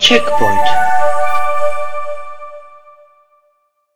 Checkpoint.wav